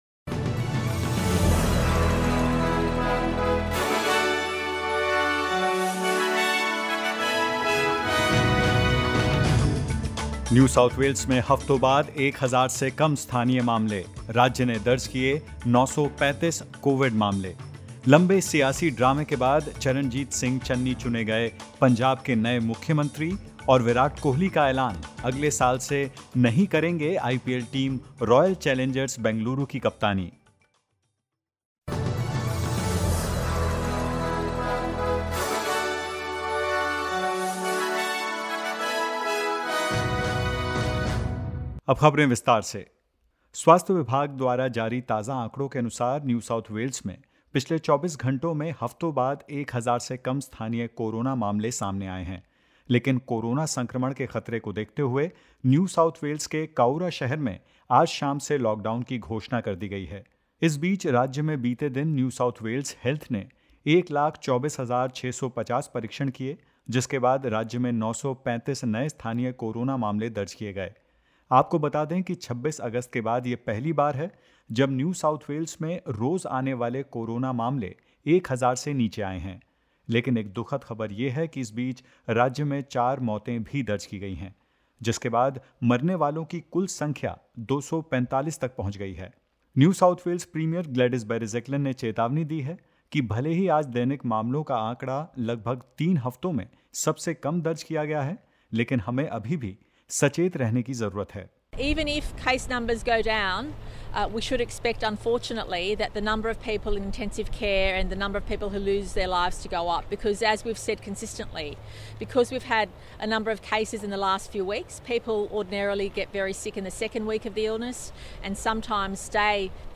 In this latest SBS Hindi News bulletin of Australia and India: Regional Victoria's city Cowra goes into lockdown from 5 pm; NSW records 935 new locally acquired cases and four deaths and more.